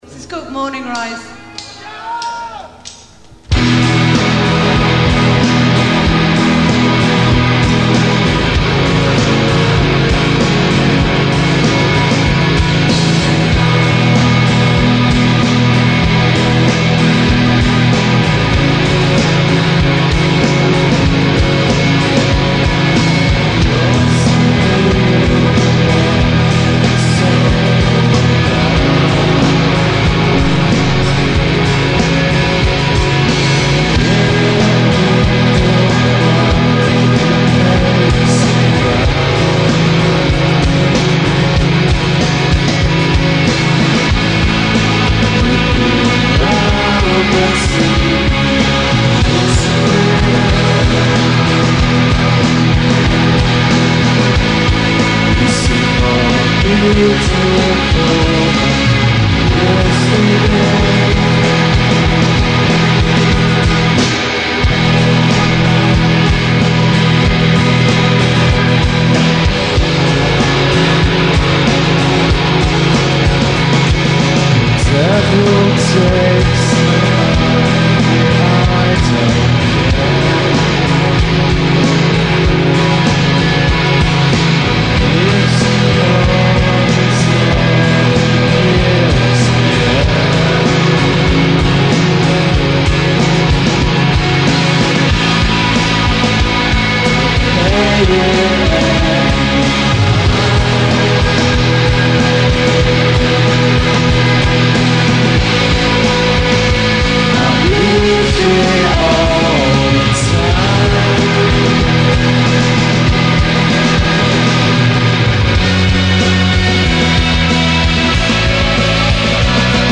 Centrum